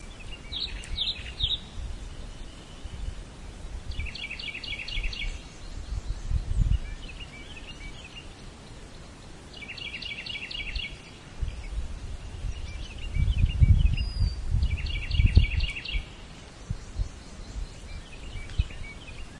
鸟类 " 啄木鸟和其他鸟类
描述：在郊区记录的鸟类包括啄木鸟。
Tag: 鸟鸣声 啄木鸟 春天 自然 现场录音